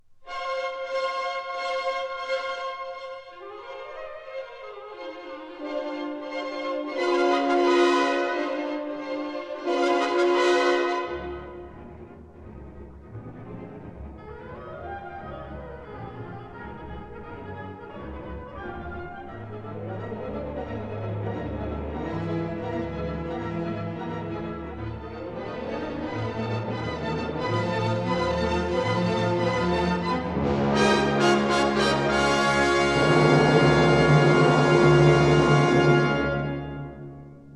conductor
1958 stereo recording